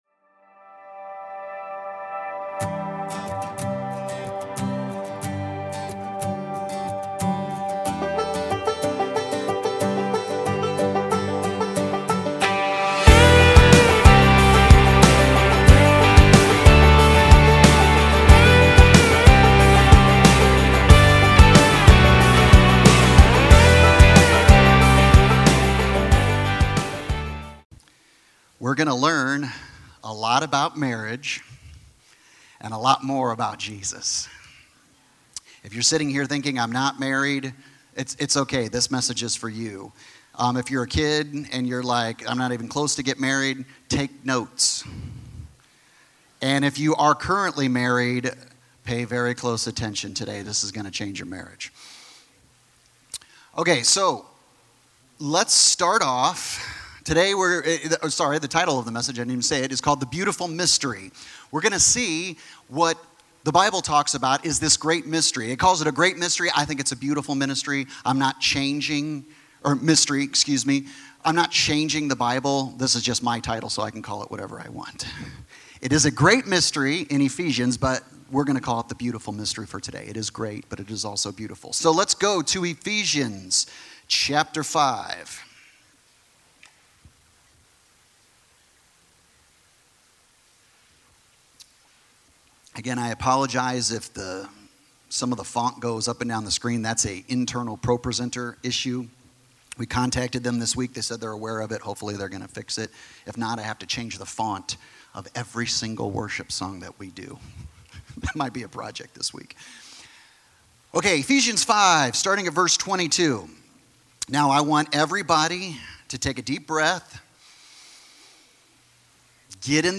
Sermons | 7 Mountains Church